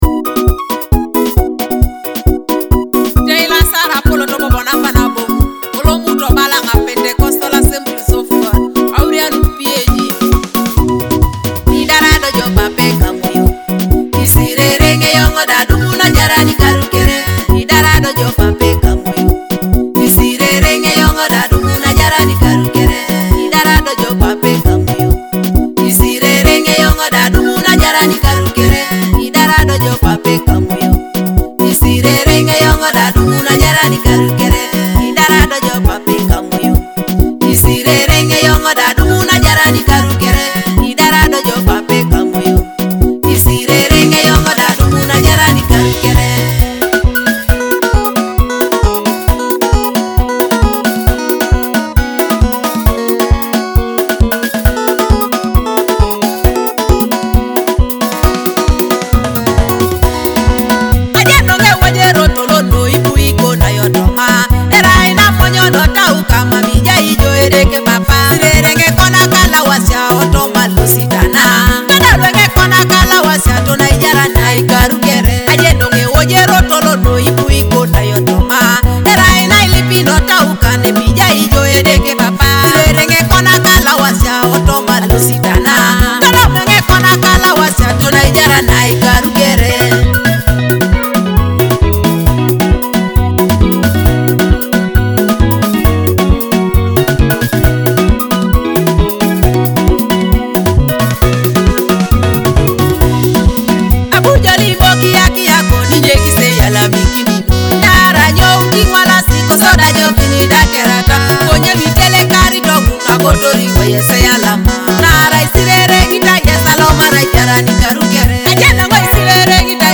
a leading Teso gospel music artist
Enjoy authentic African rhythms and uplifting gospel vibes.